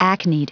Prononciation du mot acned en anglais (fichier audio)
Prononciation du mot : acned